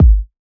Kick Mamacita.wav